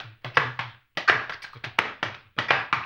HAMBONE 03.wav